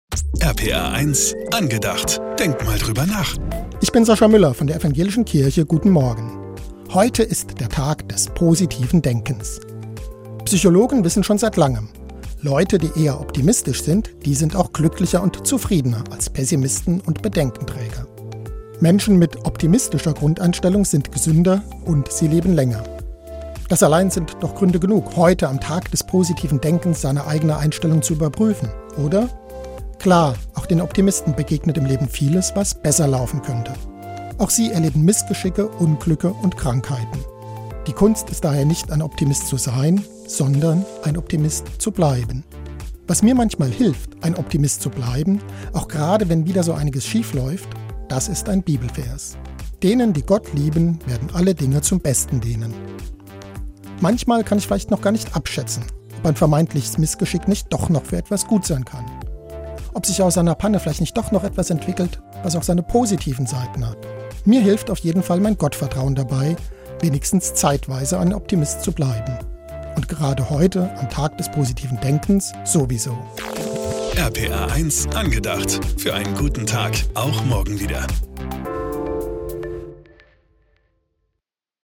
ProtCast Pfalz - Radioandachten aus Rheinland-Pfalz